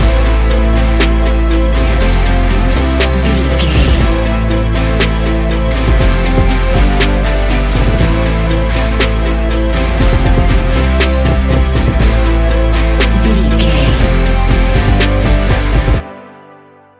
Ionian/Major
A♭
ambient
electronic
chill out
downtempo
synth
pads